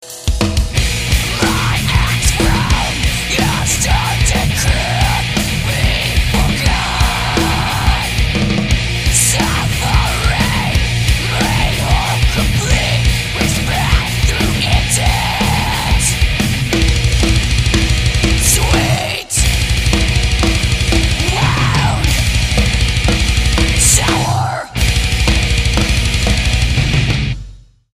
STYLE: Hard Music
This is loud and intense and on the whole very good.
Hardcore with a bit of diversity.